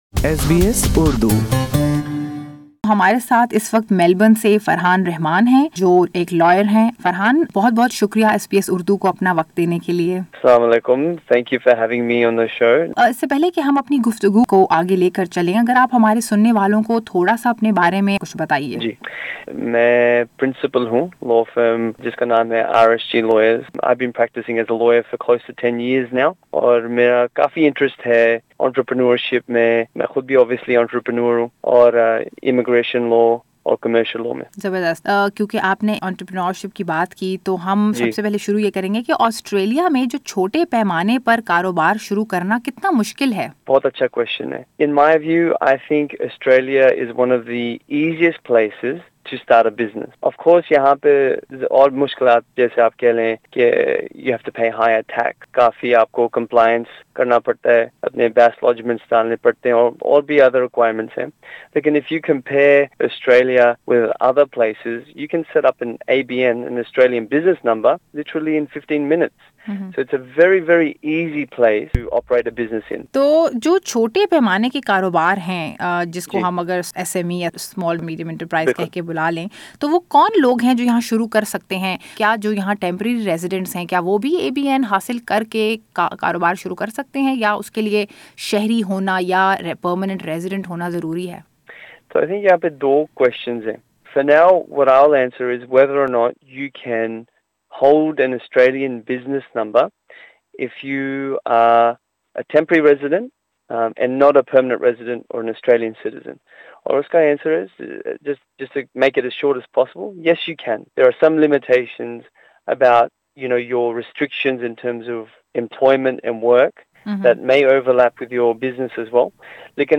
How hard is it for migrants to set-up a small business in Australia? SBS Urdu talks to a legal expert to explain the way forward for small and medium entrepreneurship in Australia.